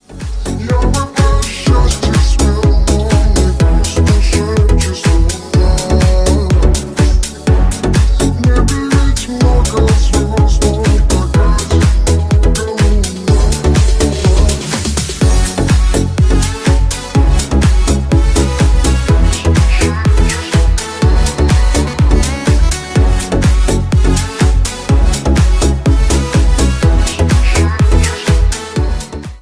Ремикс
клубные # грустные